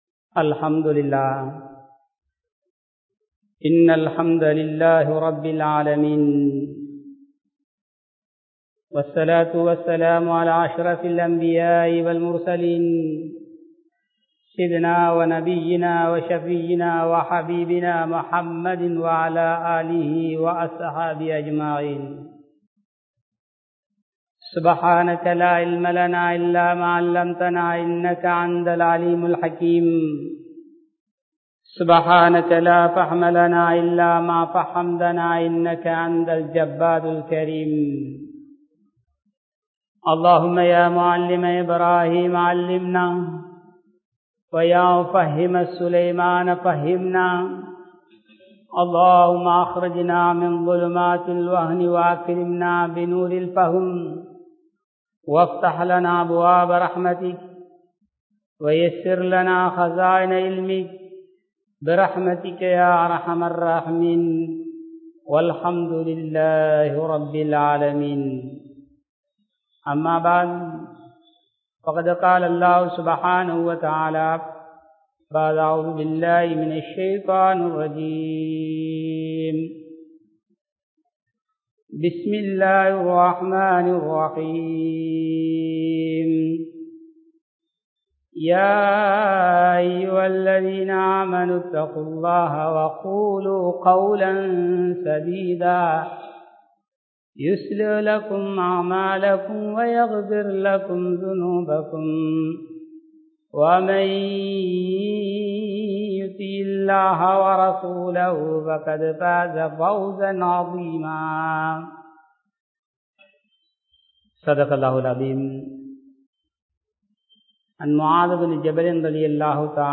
Ramalan Thantha Paadam Enna?(ரமழான் தந்த பாடம் என்ன?) | Audio Bayans | All Ceylon Muslim Youth Community | Addalaichenai